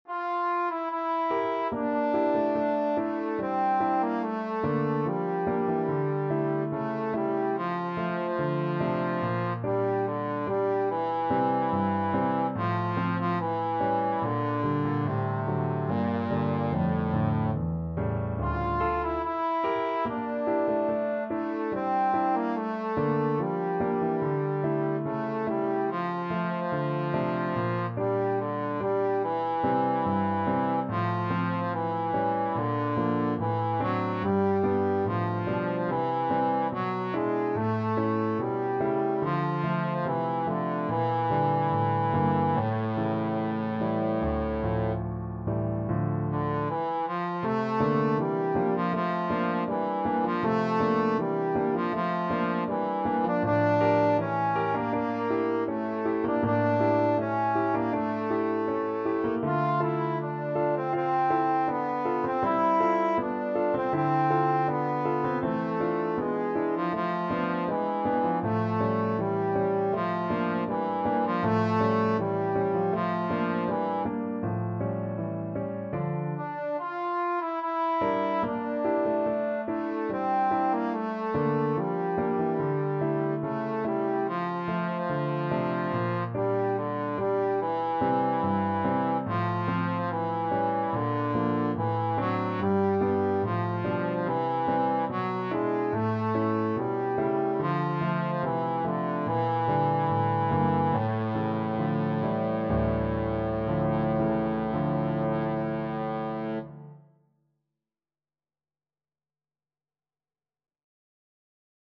Trombone
F major (Sounding Pitch) (View more F major Music for Trombone )
2/4 (View more 2/4 Music)
~ = 72 Andantino (View more music marked Andantino)
canon-in-e-minor_TBNE.mp3